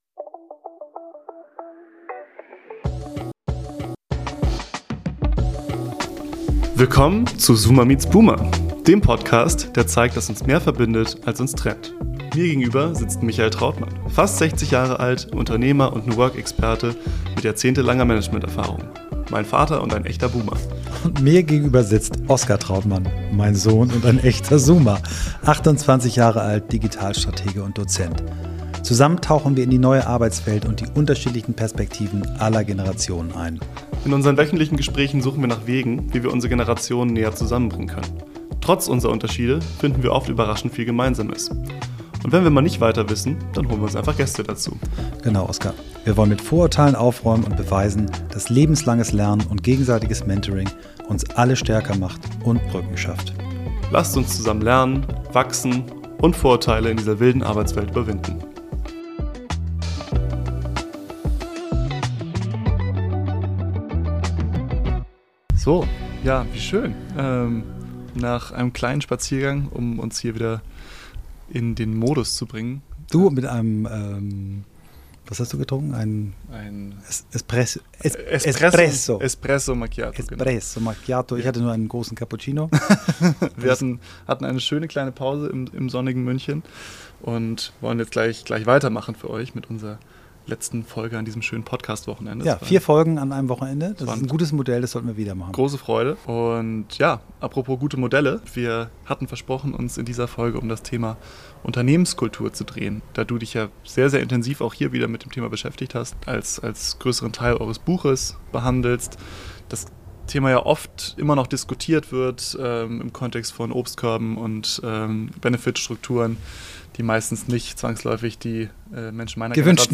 In unserer heutigen Folge sprechen wir über das große Thema Unternehmenskultur. In den vier Episoden, die wir in München aufgenommen haben, scheint uns die Hitze etwas zu Kopf gestiegen zu sein. Fest steht: Wir reden noch schneller, als sonst.